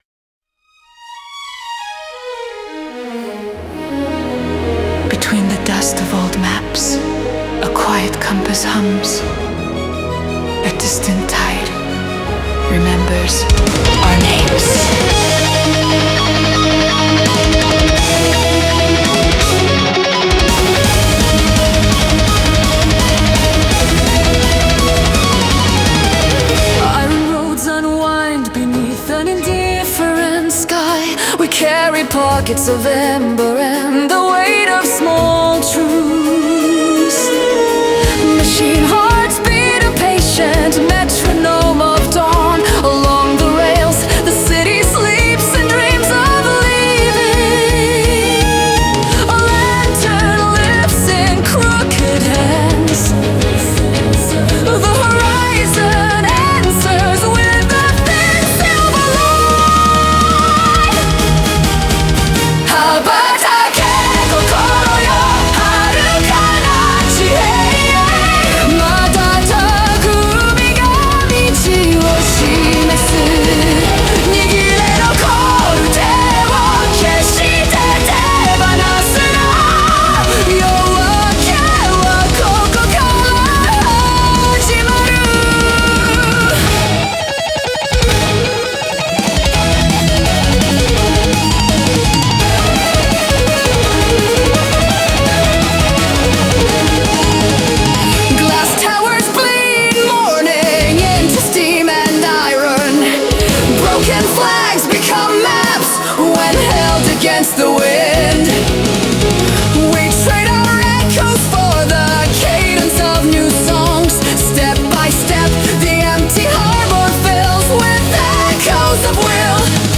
Symphonic Metal